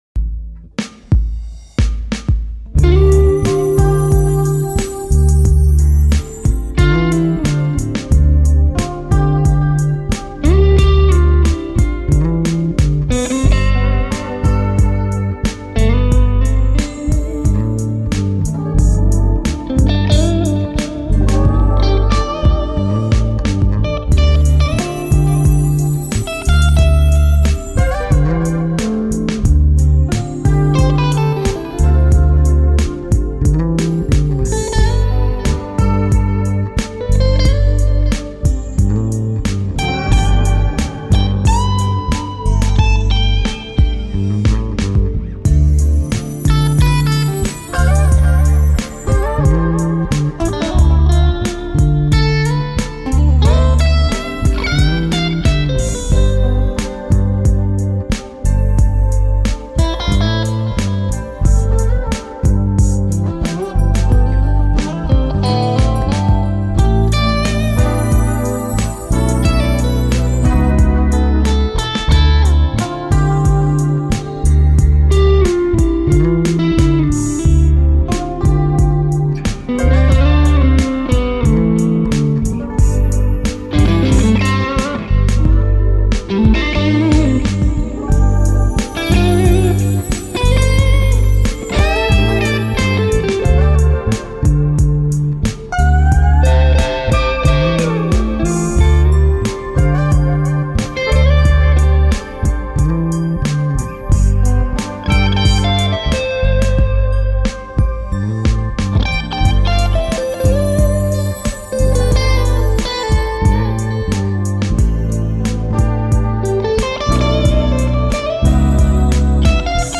Die Gitarre ist meine alte Tokai Strat, sie geht in den linken Kanal des Matchless DC30, am Anfang mit Volume so gegen 9 Uhr, danach bei ca. 12 Uhr und im letzten Drittel ist der Volumeregler des Matchless so bei 3 Uhr angekommen.
Als Mikro habe ich ein SM57 vor den Greenback gestellt, nah dran. Eine Prise Reverb und Delay aus Logic, das war es dann auch.